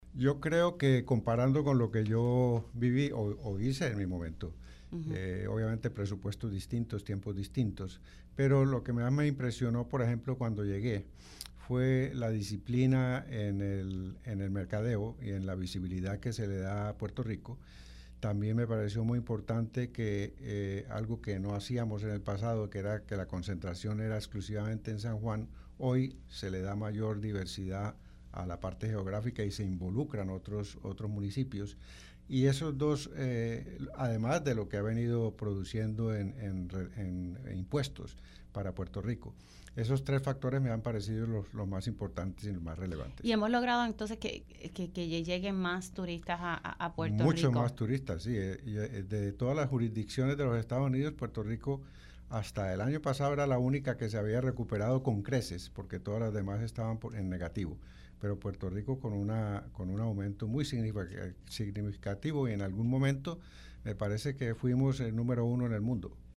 Si no lo produzco, pues me lo quitan“, indicó el hotelero en entrevista para Pega’os en la Mañana.